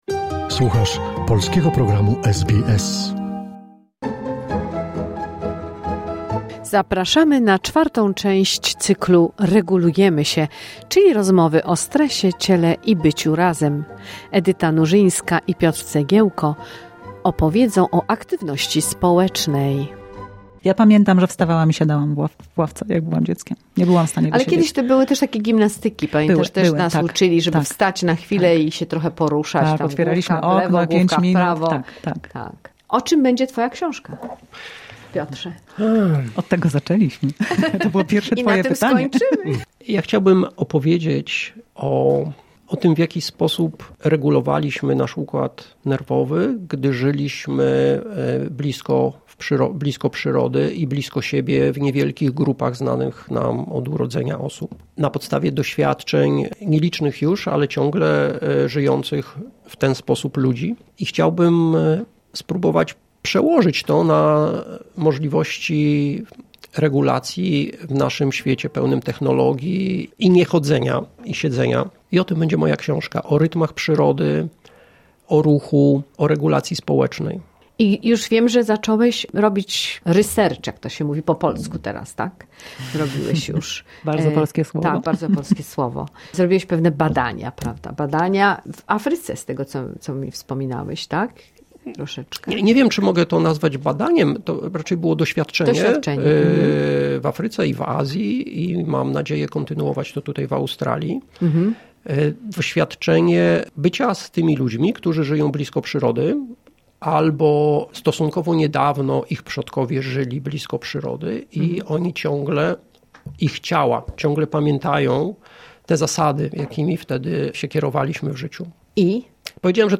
Posłuchaj całej godzinnej audycji radiowej tutaj